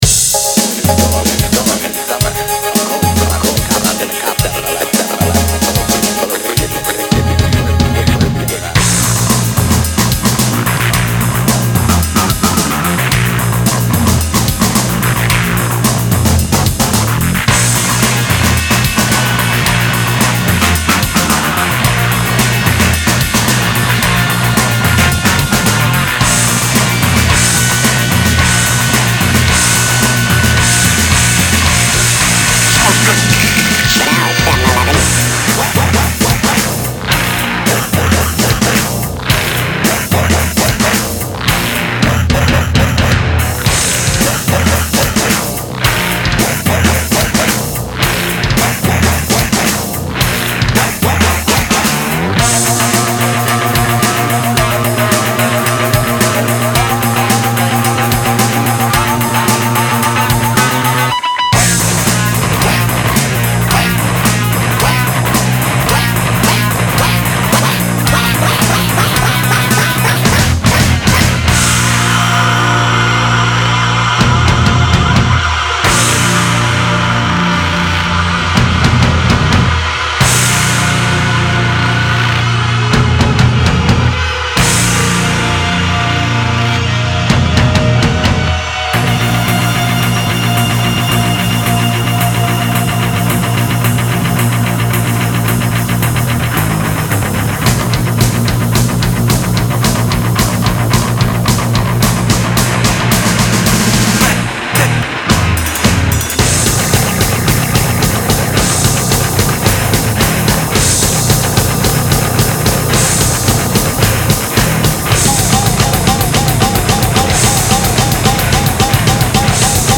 Audio QualityPerfect (High Quality)
Guitar
Bass
BPM: 55-110
Several vocal samples can be heard.